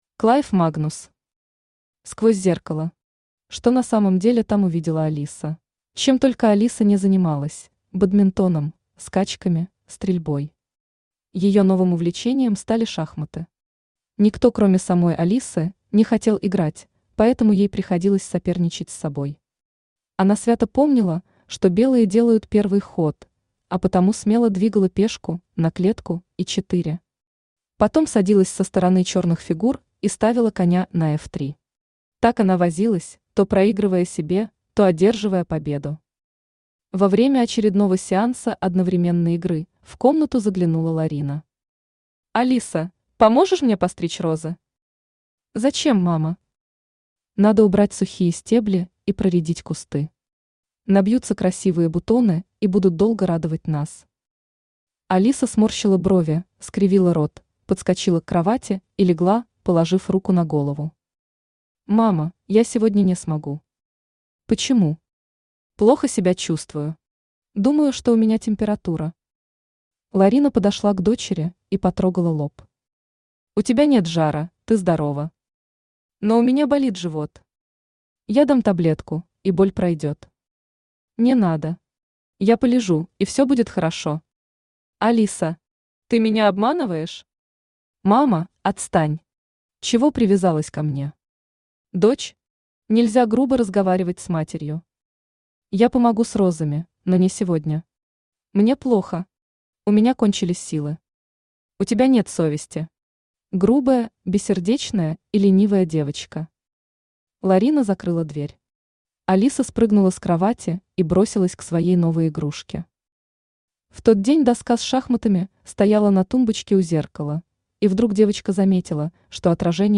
Аудиокнига Сквозь зеркало. Что на самом деле там увидела Алиса | Библиотека аудиокниг